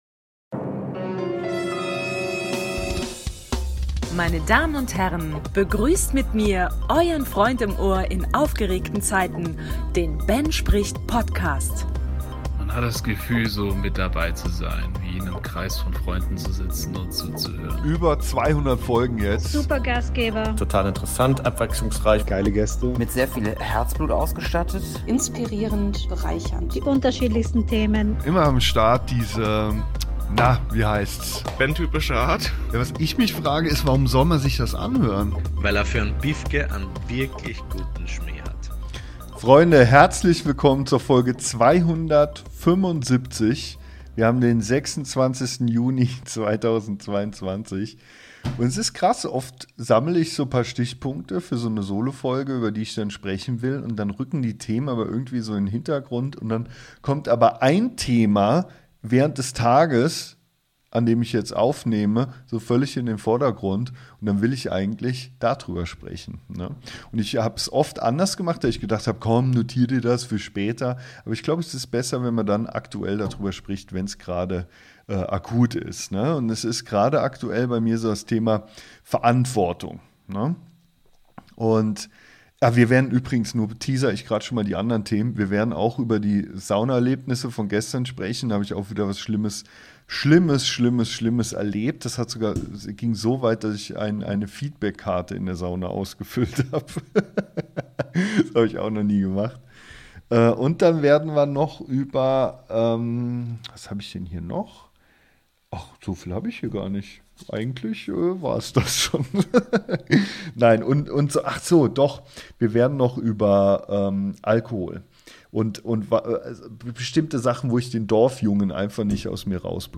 Ich rede mich ein wenig in Rage.